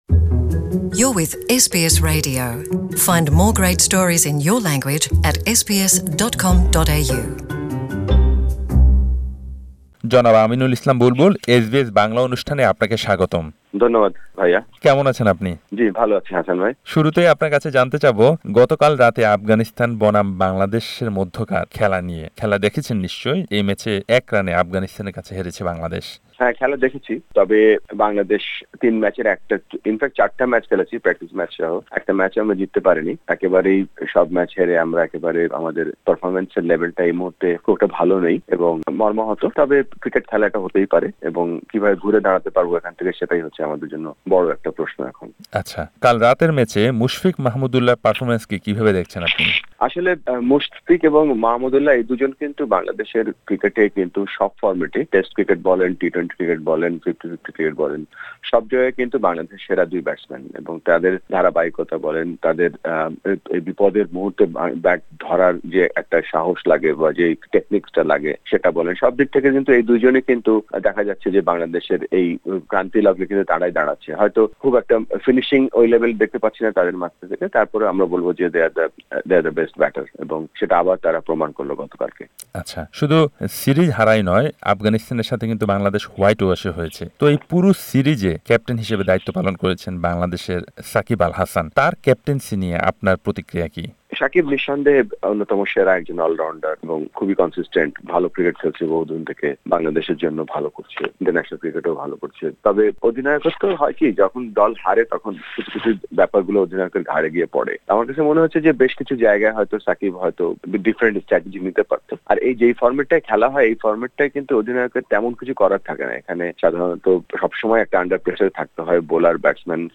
পুরো সিরিজে বাংলাদেশ দলের অবস্থা হ-য-ব-র-ল ছিল বলে মন্তব্য করেছেন বাংলাদেশ ক্রিকেট দলের সাবেক অধিনায়ক এবং আইসিসি এশিয়া বিভাগের ডেভেলপমেন্ট ম্যানেজার আমিনুল ইসলাম বুলবুল। বাংলাদেশ- আফগানিস্তানের মধ্যকার সিরিজ নিয়ে এসবিএস বাংলার সাথে কথা বলেছেন তিনি।